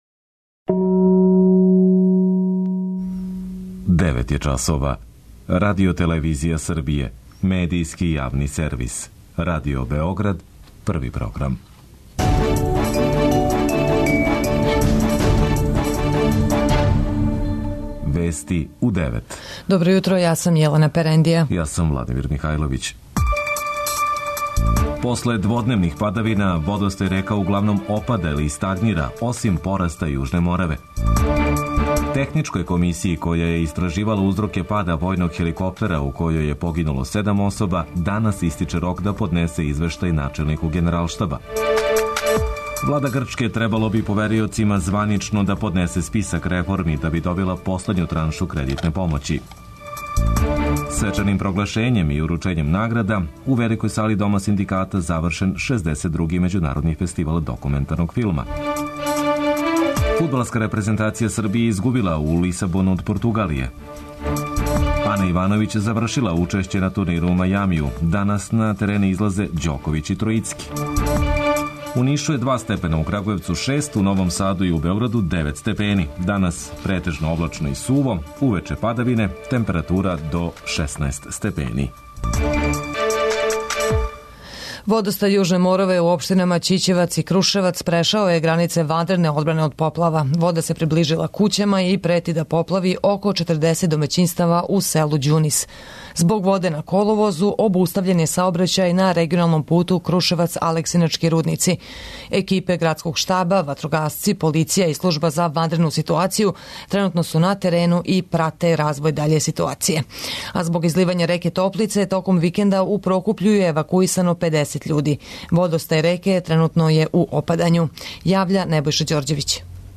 Уредници и водитељи